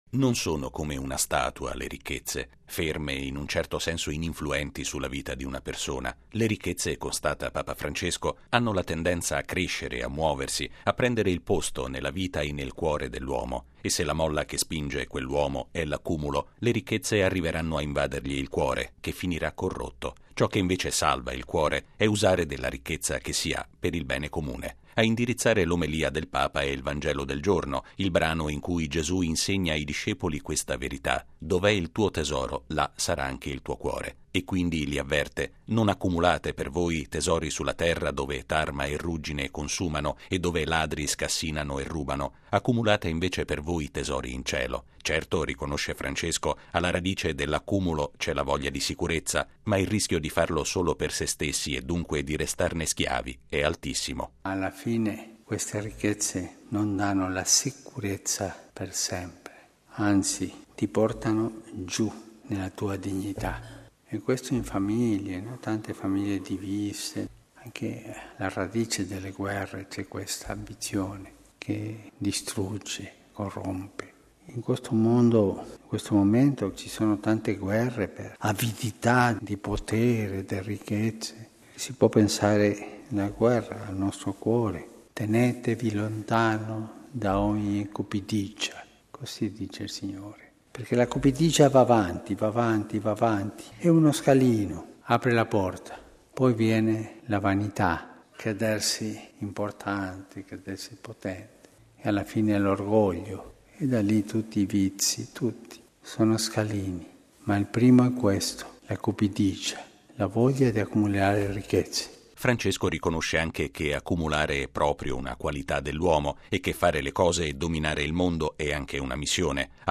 È una delle considerazioni di fondo dell’omelia di Papa Francesco alla Messa del mattino, celebrata in Casa Santa Marta. Il servizio